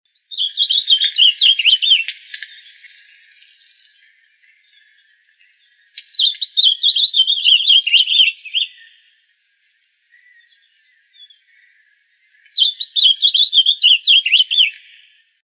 Glaucous-blue Grosbeak (Cyanoloxia glaucocaerulea)
Life Stage: Adult
Location or protected area: Reserva Ecológica Costanera Sur (RECS)
Condition: Wild
Certainty: Recorded vocal